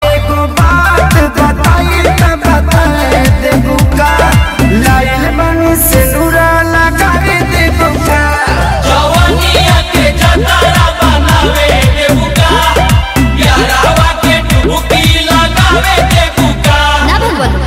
BHOJPURI SONG RINGTONE for your mobile phone in mp3 format.